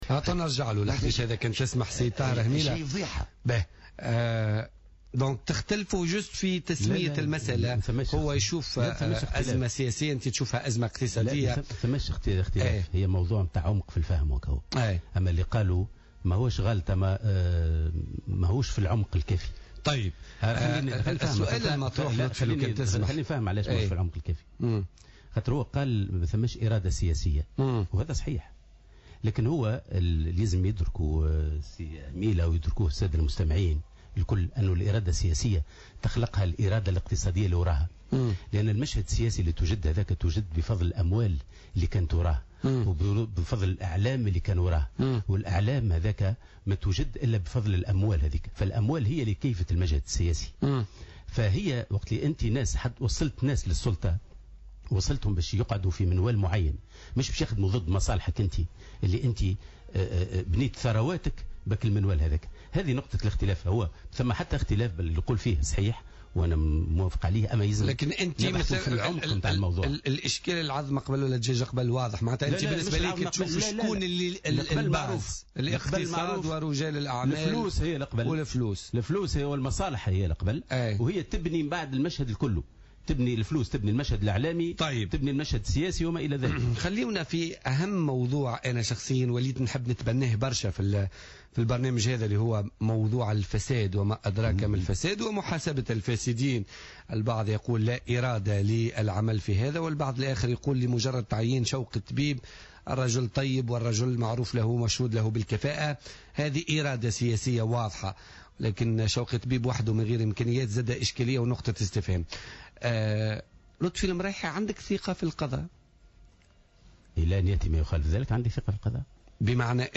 Le secrétaire général de l'Union populaire républicaine était l’invité de Politica ce mercredi 10 février 2016.